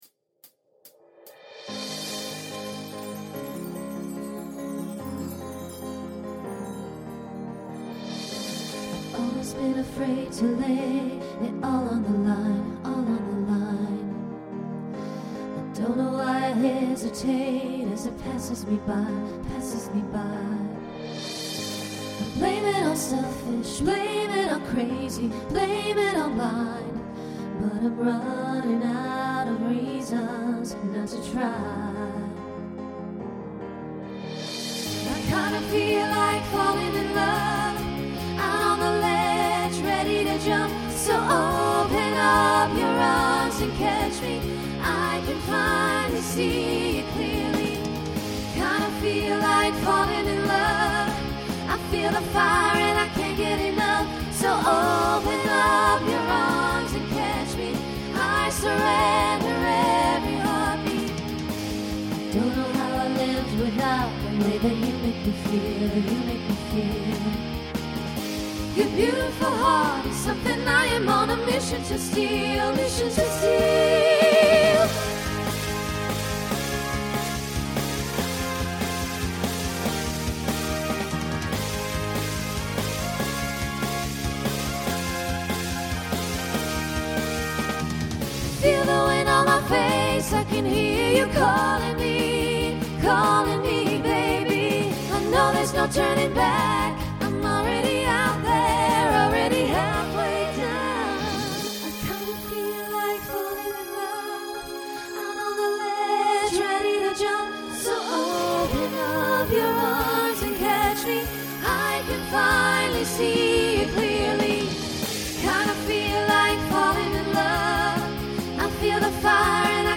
SSA/TTB
Voicing Mixed Instrumental combo Genre Pop/Dance